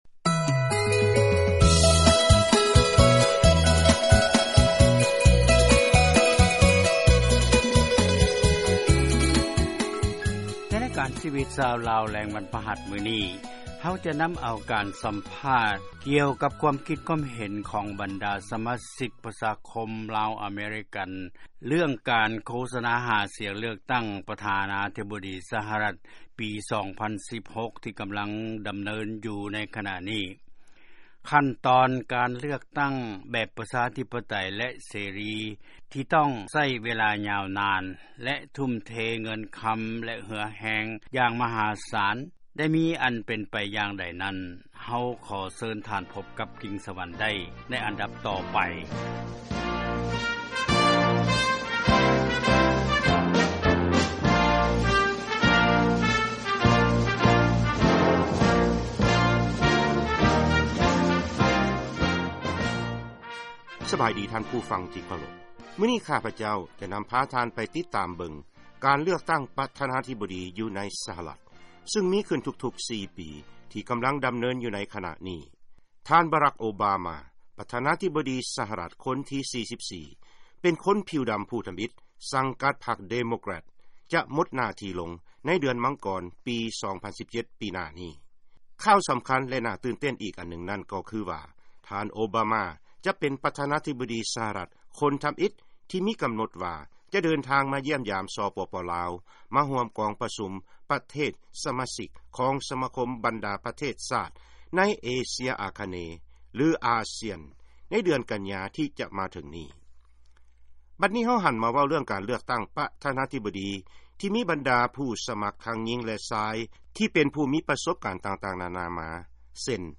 ສຳພາດ ຊາວອາເມຣິກັນ ເຊື້ອສາຍລາວ ກ່ຽວກັບ ການເລືອກຕັ້ງ ປ. ສະຫະລັດ ປີ 2016